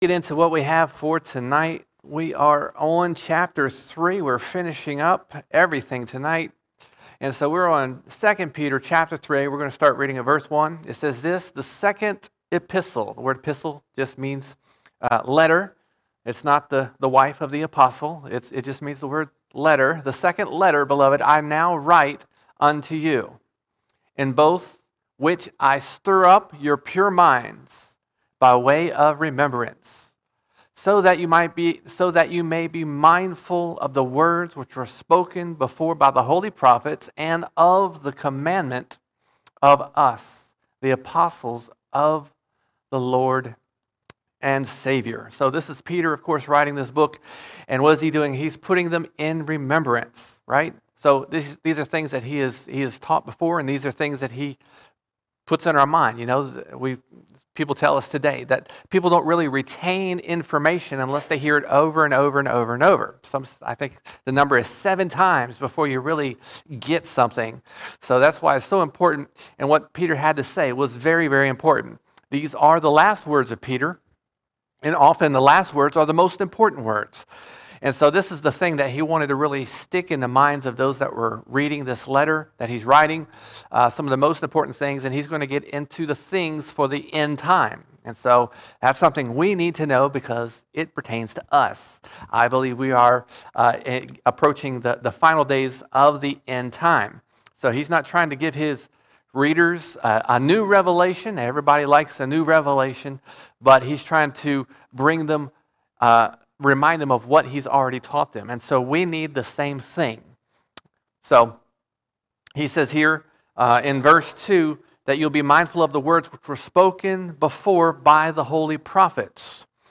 24 March 2022 All Sermons 2 Peter 3:1 to 3:18 2 Peter 3:1 to 3:18 Peter warns us of the last days when Jesus returns with fire to destroy His enemies.